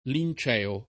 linceo [l&n©eo] agg.